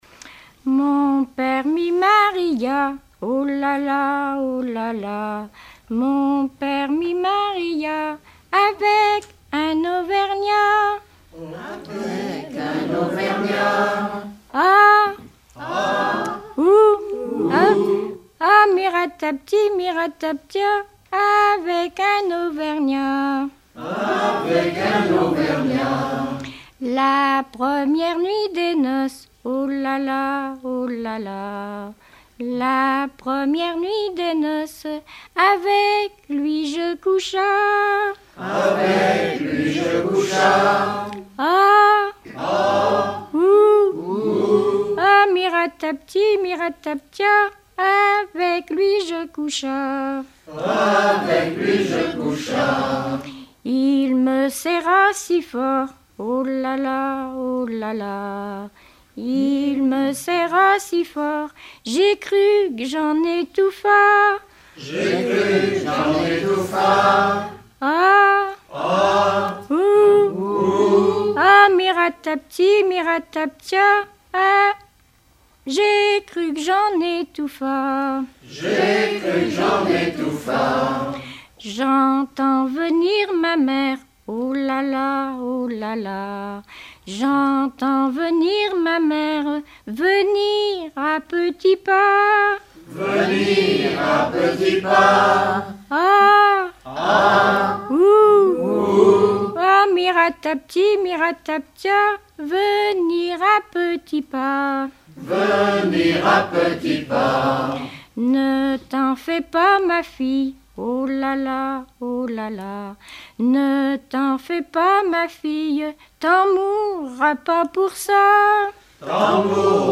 Mémoires et Patrimoines vivants - RaddO est une base de données d'archives iconographiques et sonores.
Regroupement de chanteurs du canton
Pièce musicale inédite